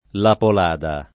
[ la pol # da ]